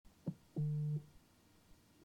Звуки вибрации iPhone
iPhone XR с акцентом